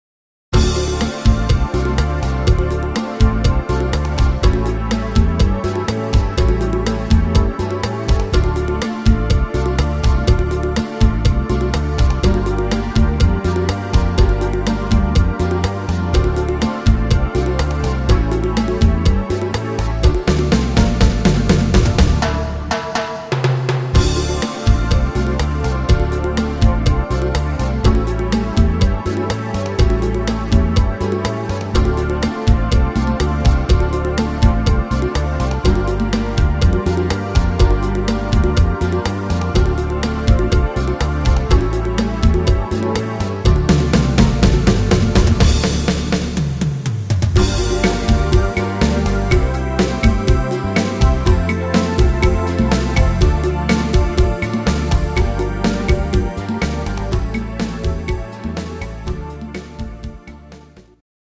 (instr. Gitarre)